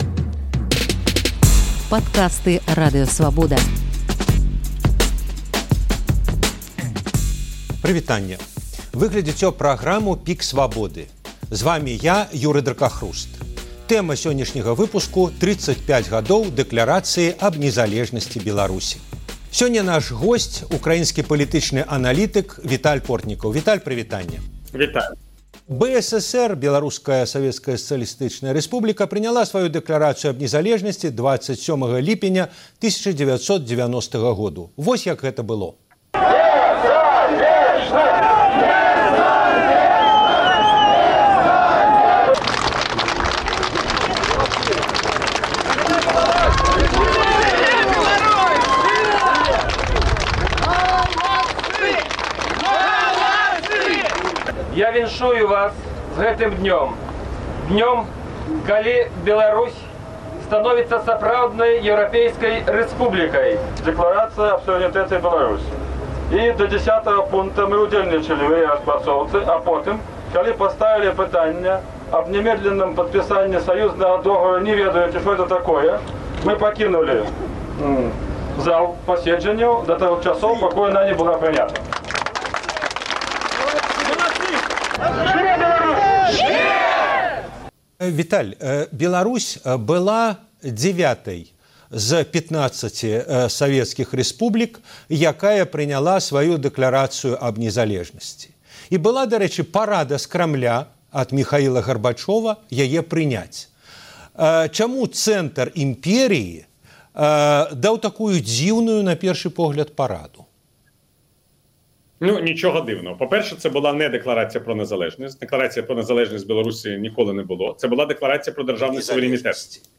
Чаму і як распаўся СССР – гутарка зь Віталём Портнікавым